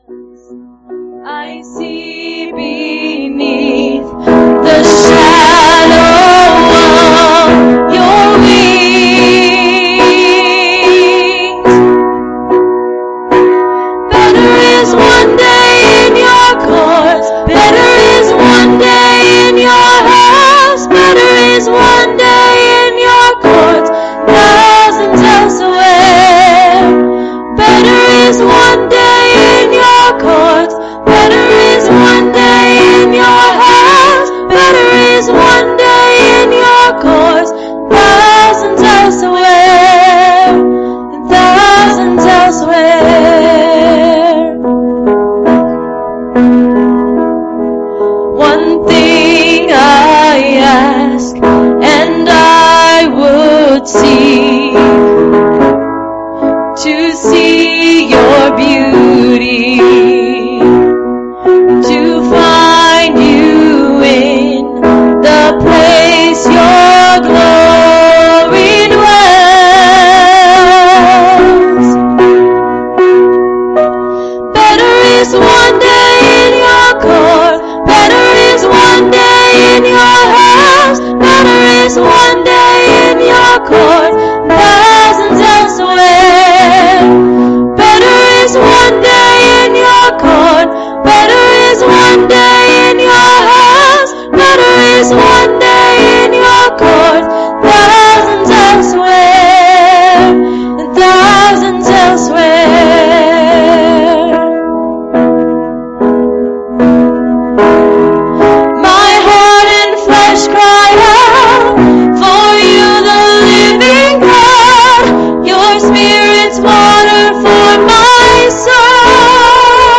Please, click on the arrow below to here this week's service.